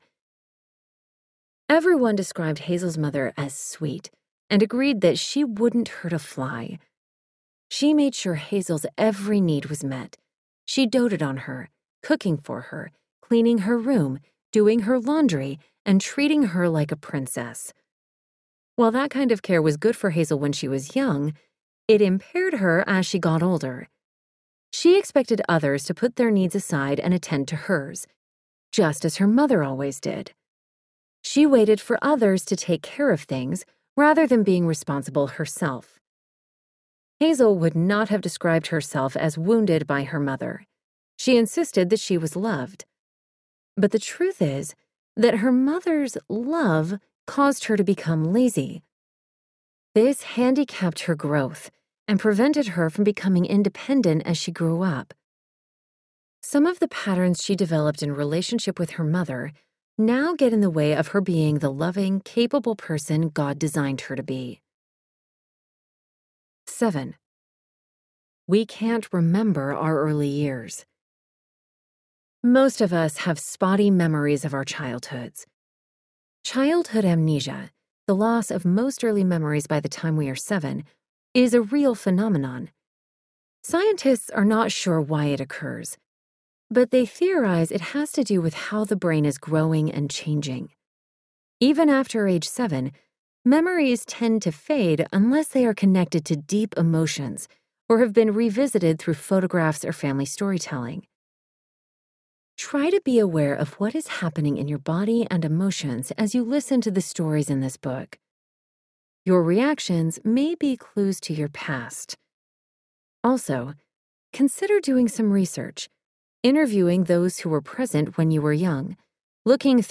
It’s Momplicated Audiobook
6.80 Hrs. – Unabridged